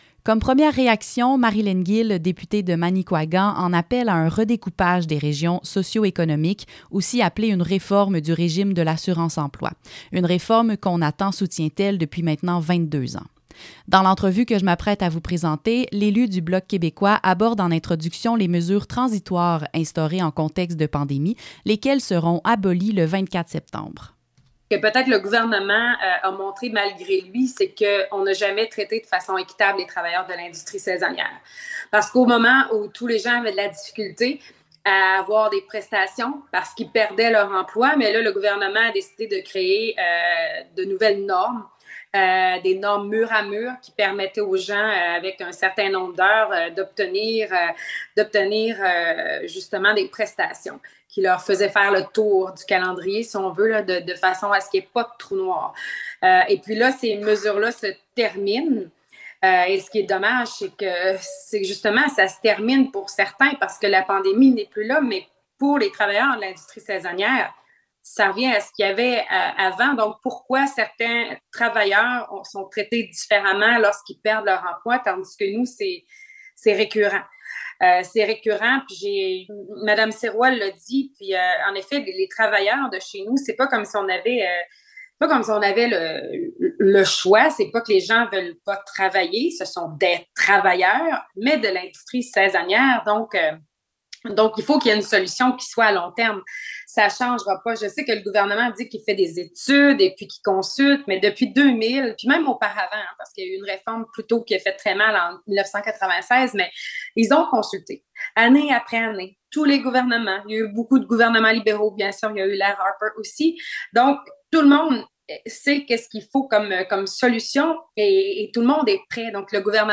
La députée de Manicouagan, Marilène Gill, réagit au reportage que CJTB a diffusé concernant le régime de l’assurance-emploi et ses impacts sur les travailleurs bas-côtiers, et continue de réclamer une réforme de celui-ci.
Gill-ass-emploi-segment-radio.mp3